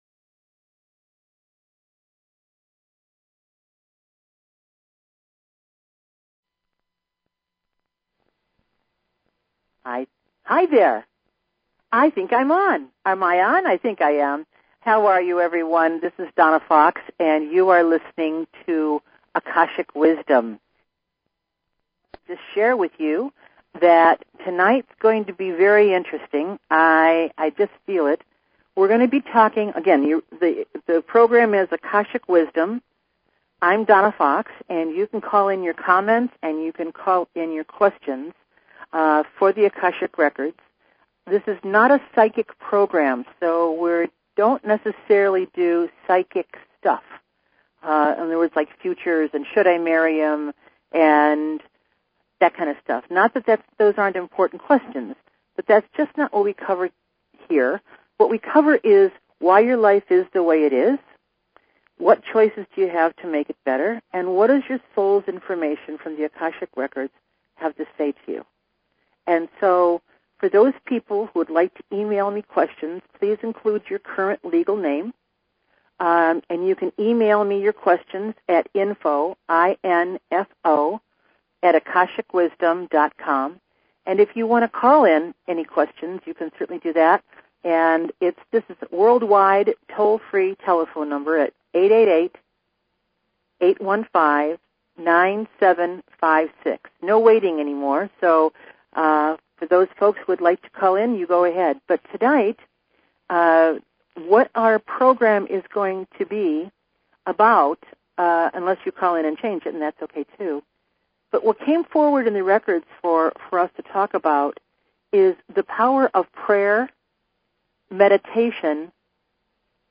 Talk Show Episode, Audio Podcast, Akashic_Wisdom and Courtesy of BBS Radio on , show guests , about , categorized as